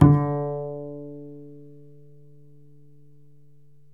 DBL BASS EN3.wav